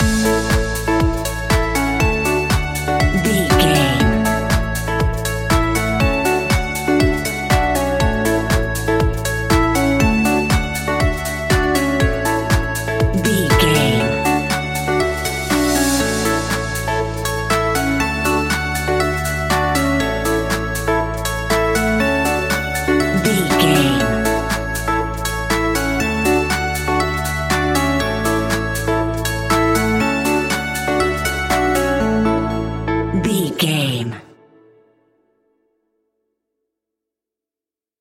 Ionian/Major
groovy
energetic
uplifting
hypnotic
electric guitar
bass guitar
drum machine
synthesiser
funky house
nu disco